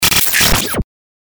FX-1130-BREAKER.mp3